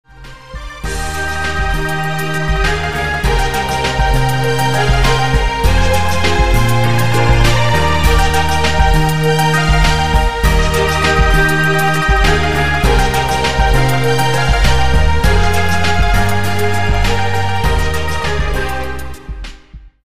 Folk music- instrumental music